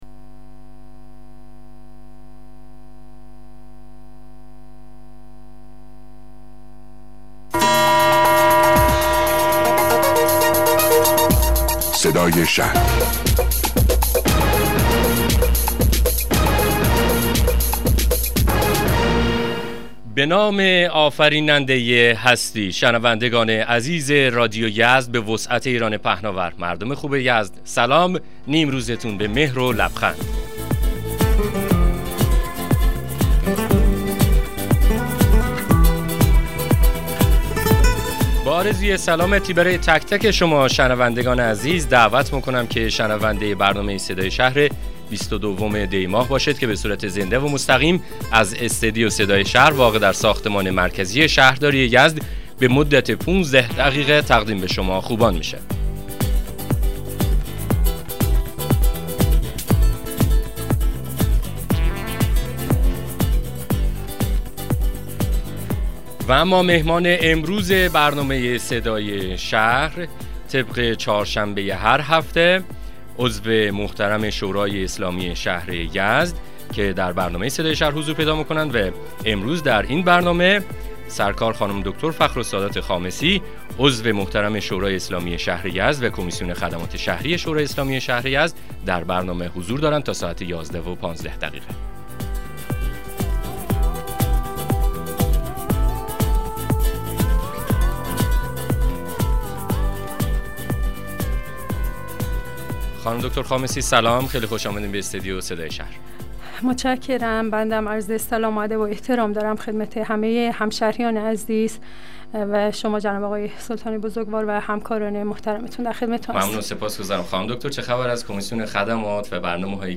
مصاحبه رادیویی برنامه صدای شهر با حضور فخرالسادات خامسی عضو شورای اسلامی شهر یزد